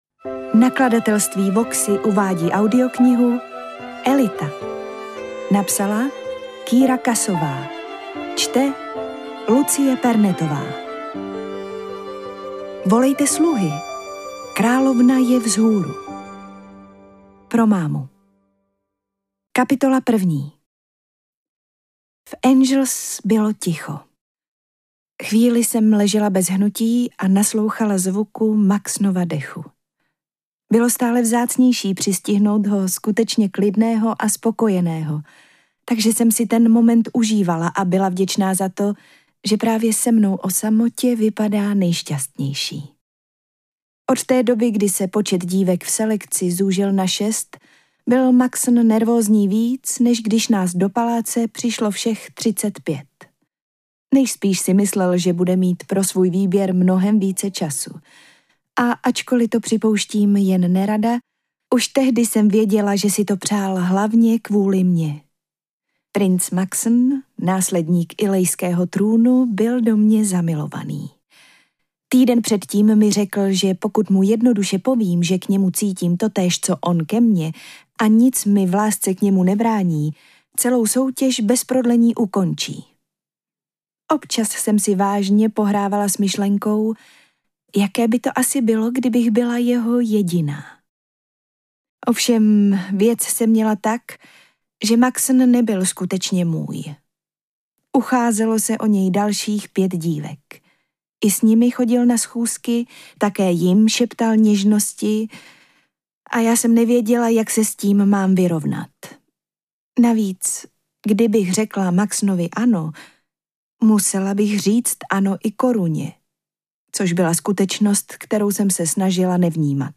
AudioKniha ke stažení, 36 x mp3, délka 9 hod. 53 min., velikost 540,8 MB, česky